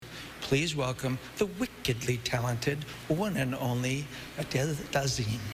A clip from John Travolta's infamous Oscar mispronunciation moment, where he butchered a celebrity's name live on stage. A classic internet meme for awkward situations.